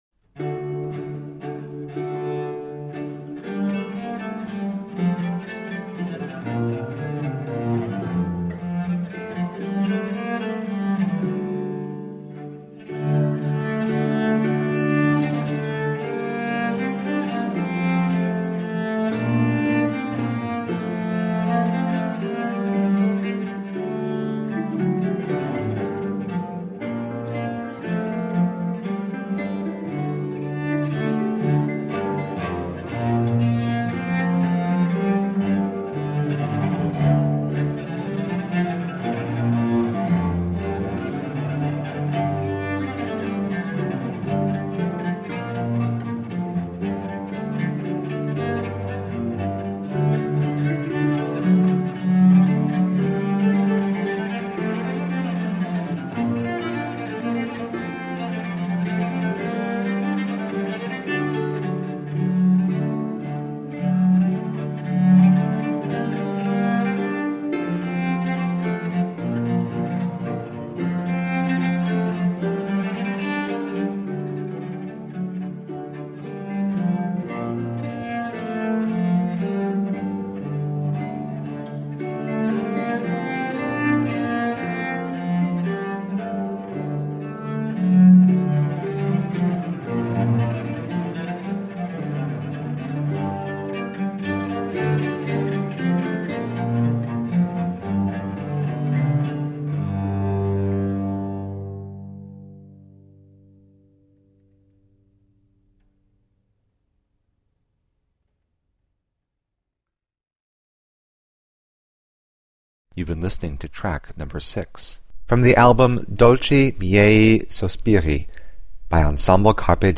Early music featuring italian affetti from switzerland.
Classical, Baroque, Instrumental
Lute, Recorder, Viola da Gamba